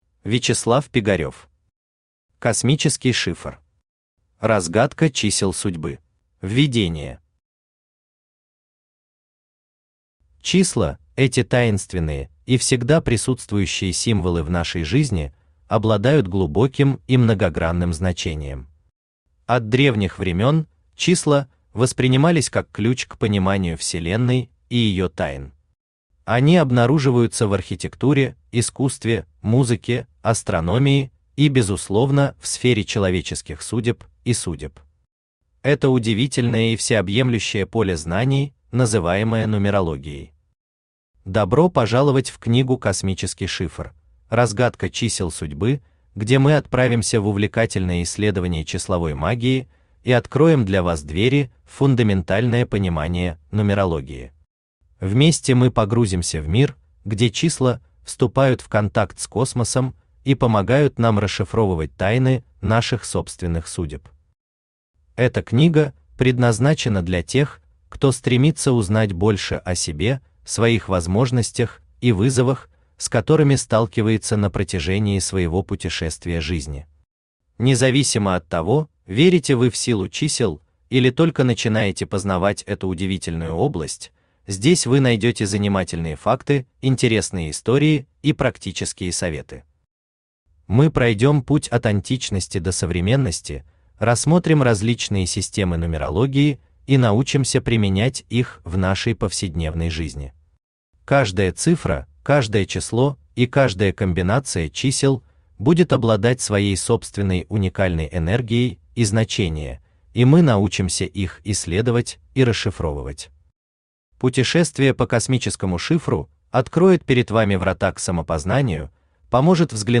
Аудиокнига Космический шифр. Разгадка чисел судьбы | Библиотека аудиокниг
Разгадка чисел судьбы Автор Вячеслав Пигарев Читает аудиокнигу Авточтец ЛитРес.